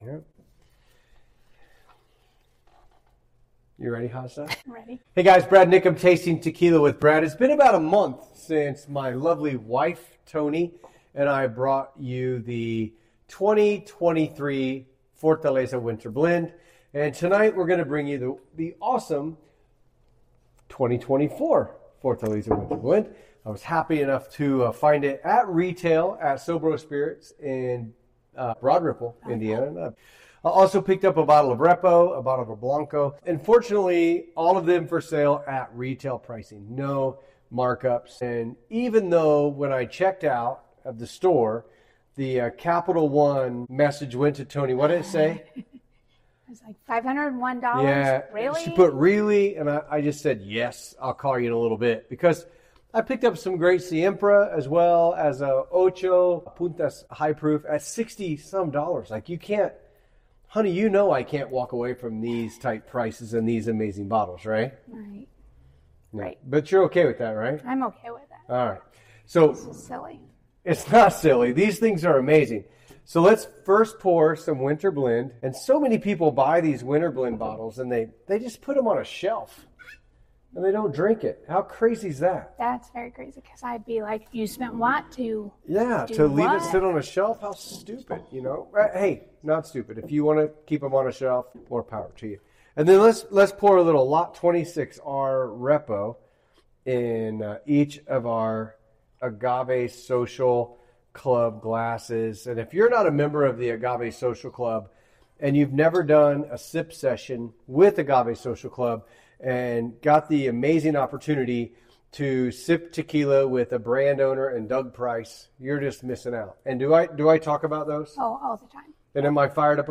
This engaging conversation explores the joy of sipping tequila rather than simply collecting it, the unique flavor profiles of different Fortaleza lots, and their exciting upcoming tequila adventures in Mexico.